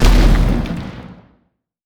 Grenade2Short.wav